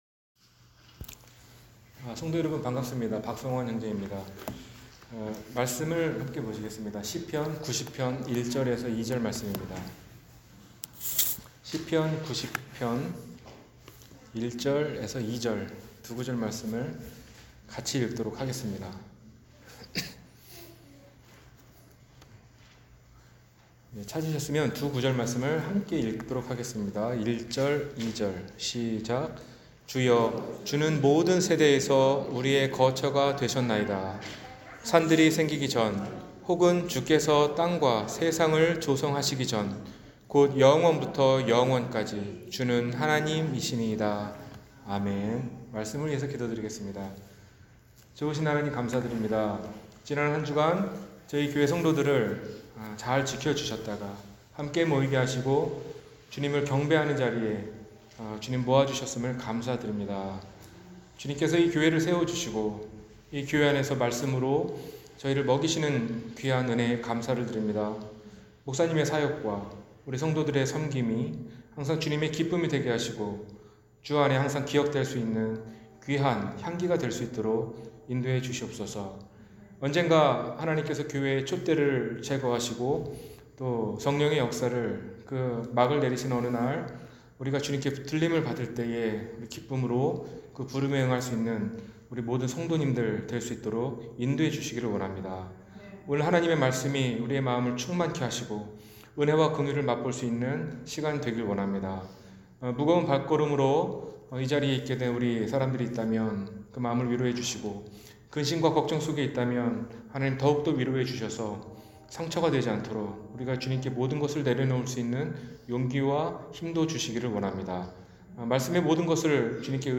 영원하신 하나님-주일설교 – 갈보리사랑침례교회